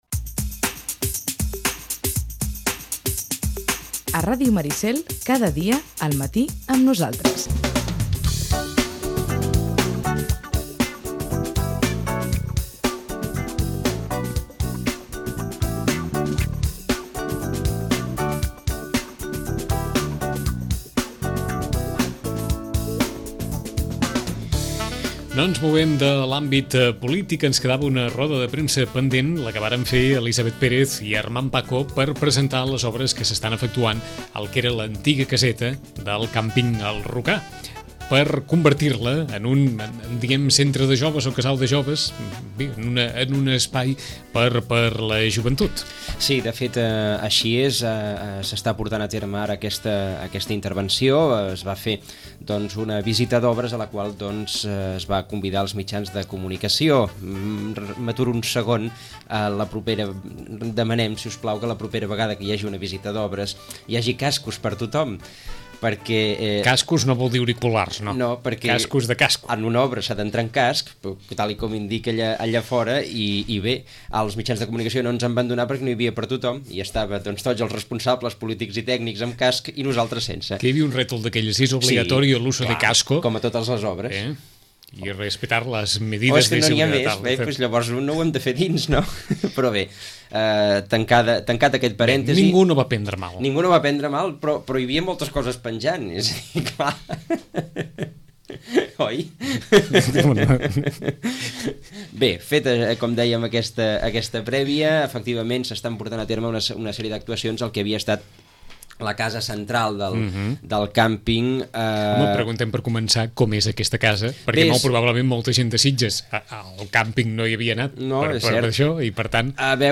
Els regidors Armand Paco i Elisabeth Pérez comenten l’estat actual de les obres de remodelació de l’antiga caseta del càmping El Rocar, que es destinarà a equipaments per a joves.